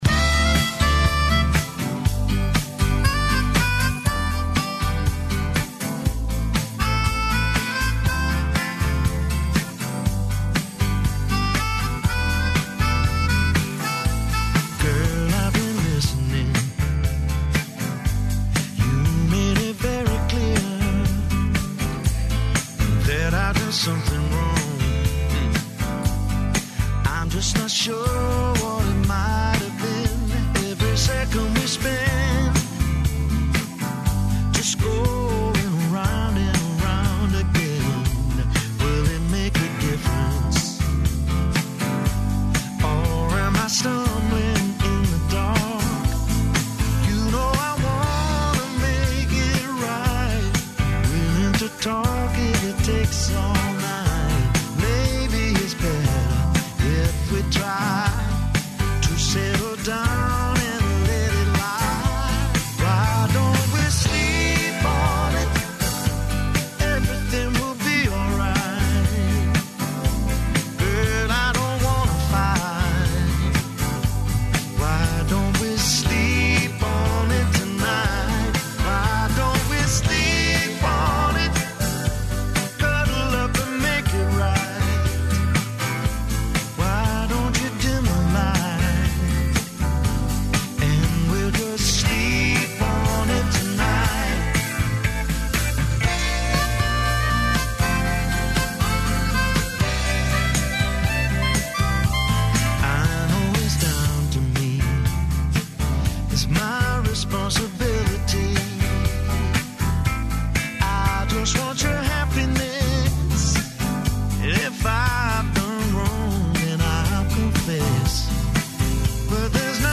О чудима разговарамо и са организаторима "Ноћи музеја" , који ће скренути пажњу на низ необичних појава и мистерија које се могу видети или се о њима може нешто сазнати у оквиру ове манифестације 21. маја.